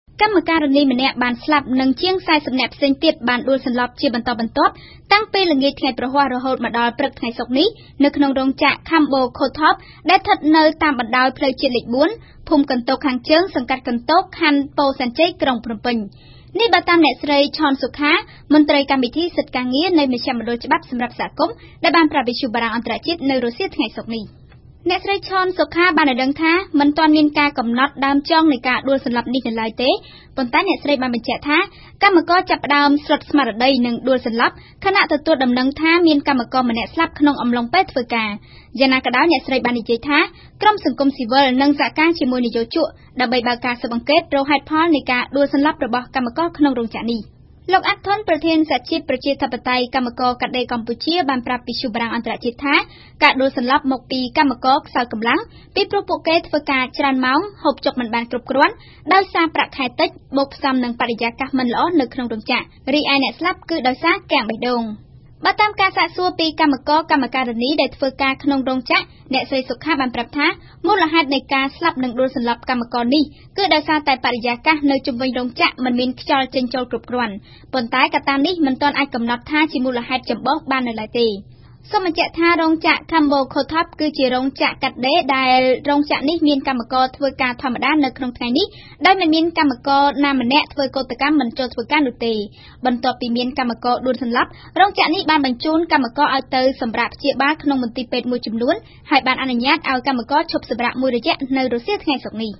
សេចក្តីរាយការណ៏របស់